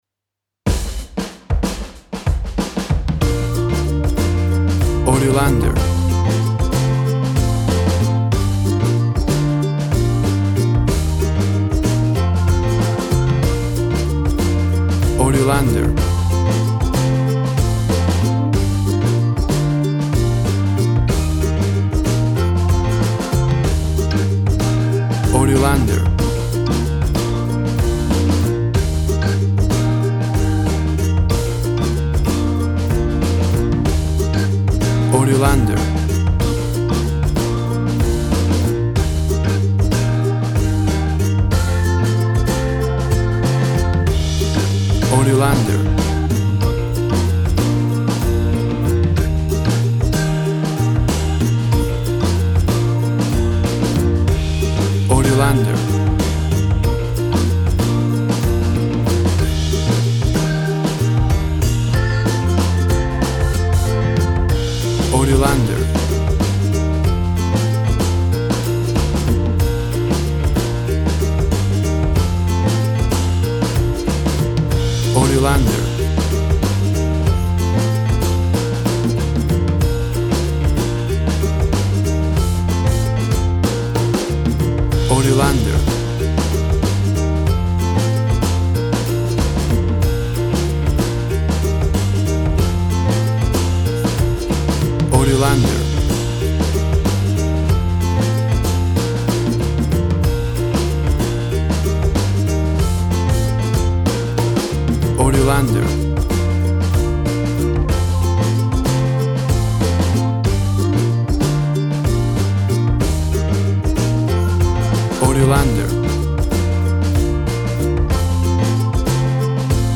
Tempo (BPM) 90